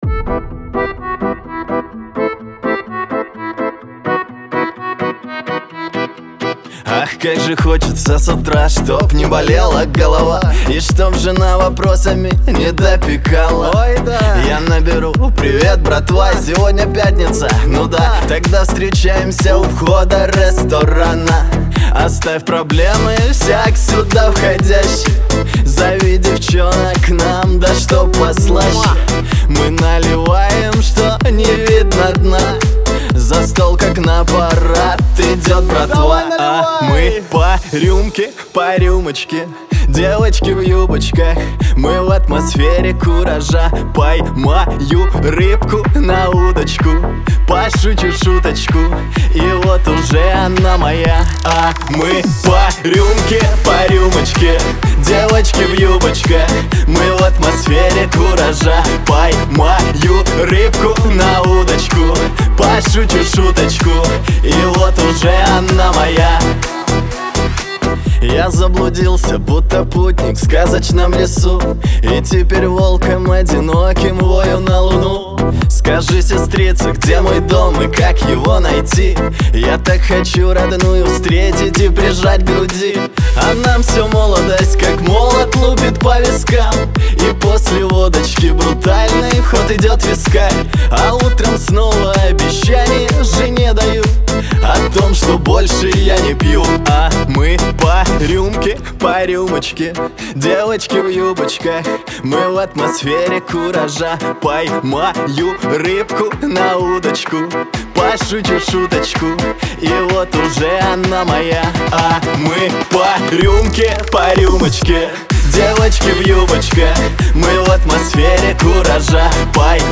Лирика